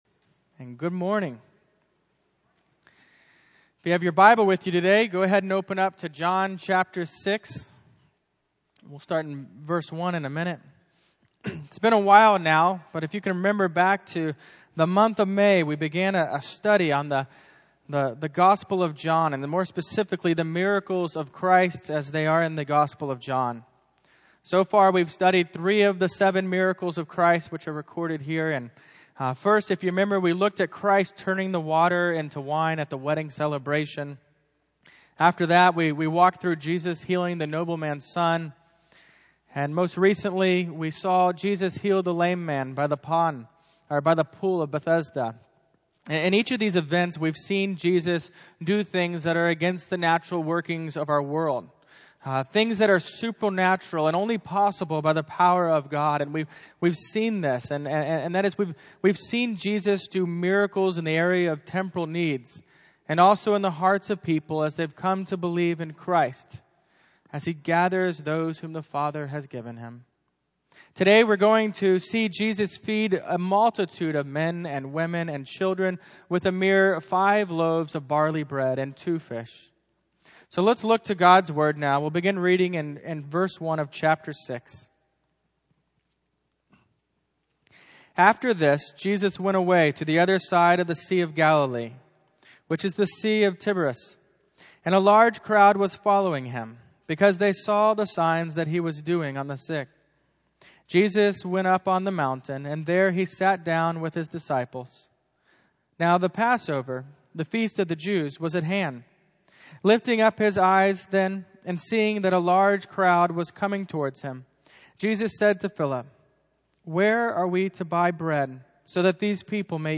John 6:1-1:15 Service Type: Morning Worship « Daniel the Lion Tamer Jesus did What?